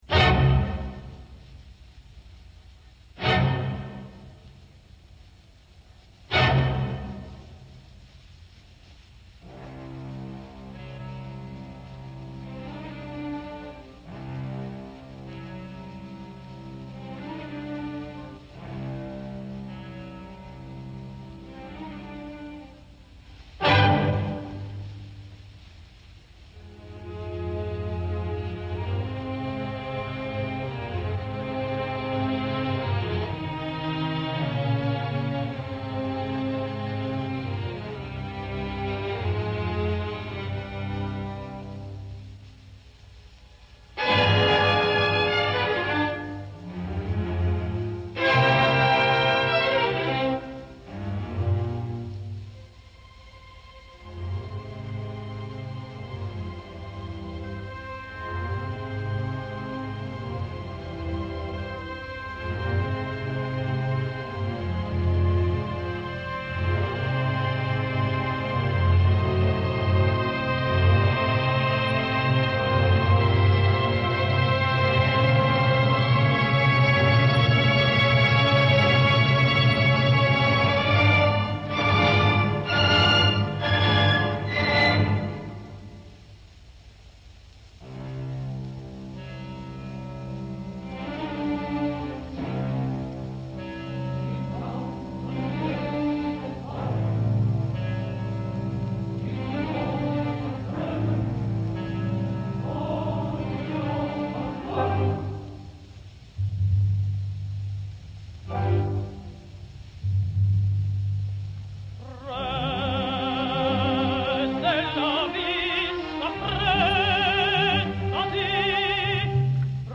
opera completa, registrazione in studio.
Coro, Ulrica